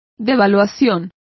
Also find out how devaluaciones is pronounced correctly.